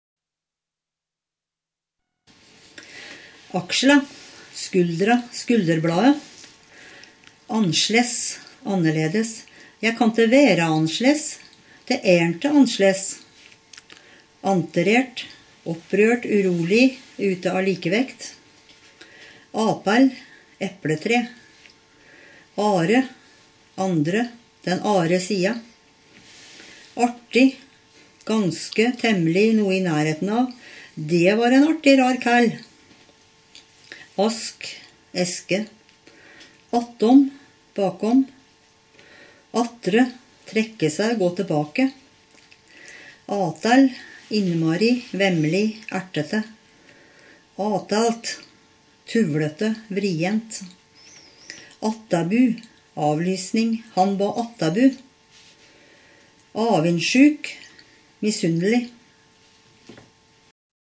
Varteig-dialekt i både skrift og tale: